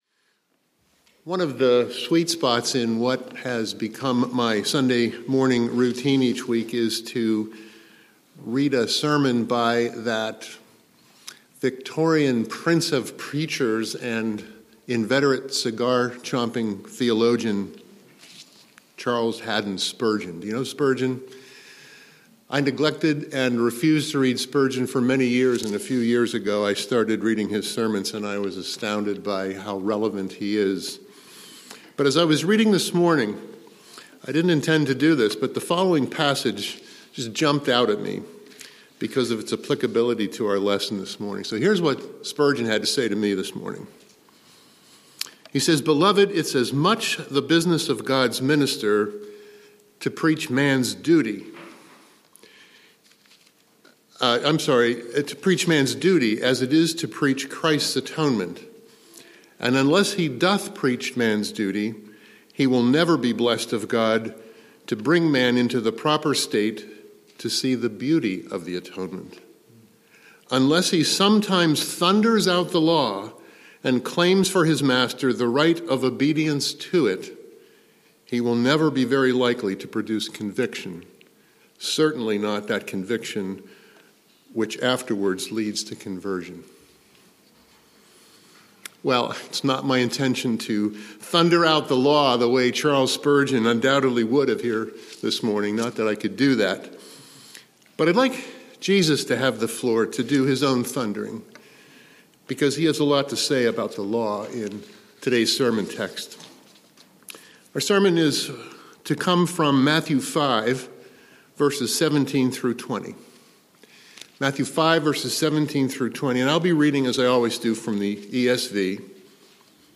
Sermon Title: “There Oughta Be A Law!”